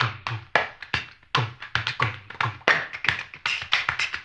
HAMBONE 10-R.wav